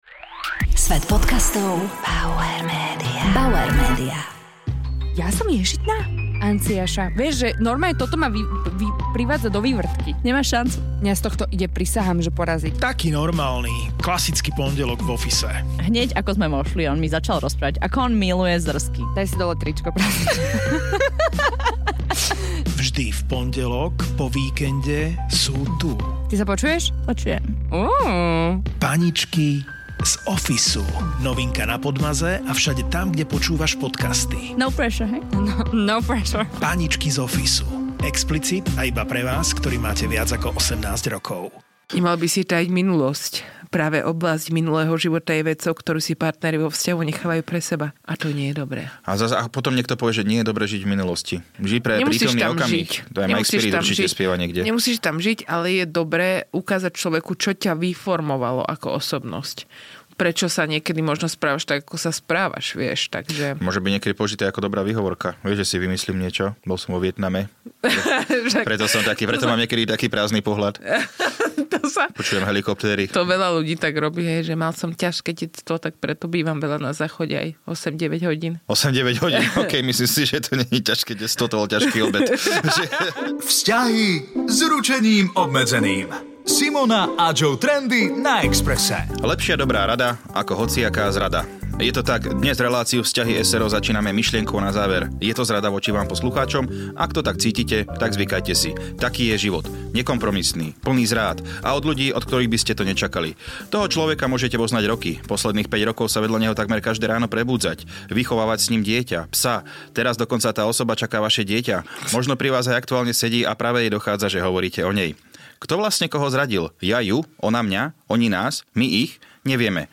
dvaja komici, ktorí tvoria pár.